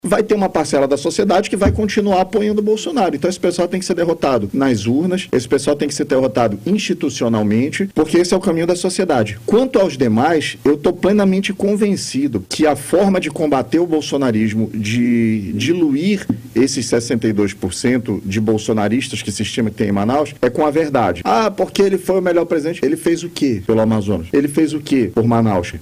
Entrevista
A entrevista exclusiva ocorreu nesta quinta-feira, 09, durante o BandNews Amazônia 1ª Edição.